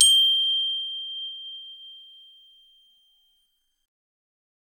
glock_medium_G6.wav